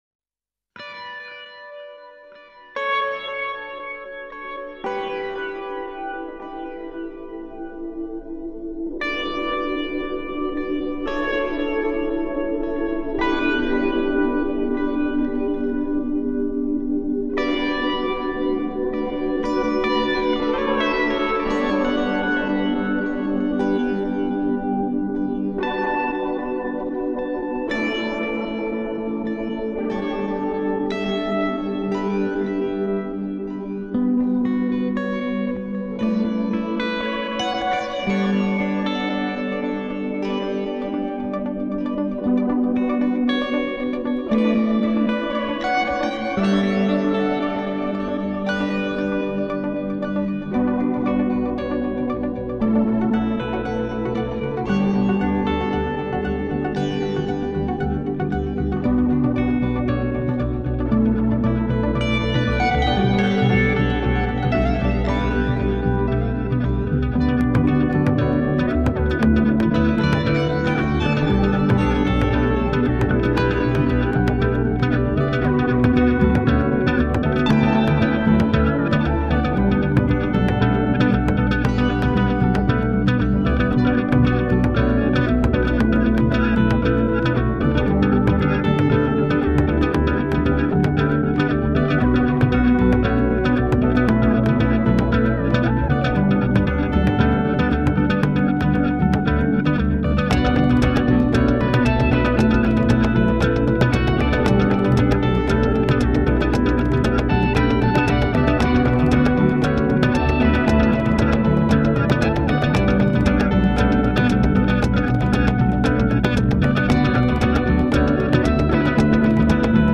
House Deep House Deep Tech Tech House Techno Electro